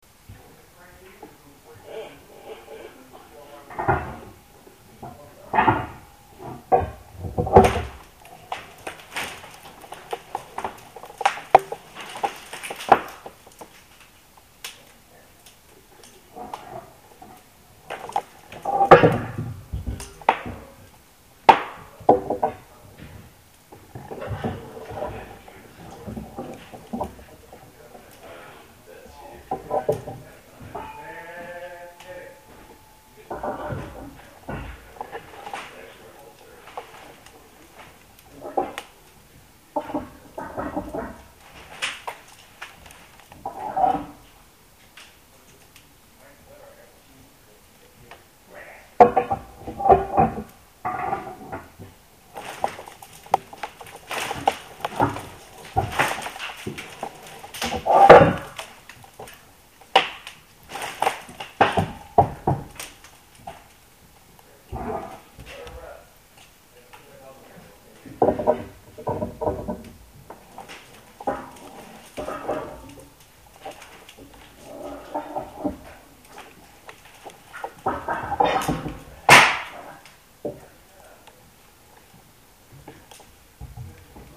The noises made by a house as it is strained are amazing; I don't know how to describe them. I recorded one of the lifting sequences, but it doesn't sound as impressive recorded as it did live. The recorded sound doesn't reproduce the "everything on every side of me is making noise" feeling I had.
They insert a metal shaft into the jack, then rotate the jack, then the house makes noise as wood is strained and pieces of plaster break and fall off of the inside of the walls. This sequence happens twice in the recording.
creaking-house.mp3